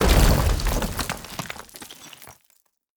Destroyed Sound.wav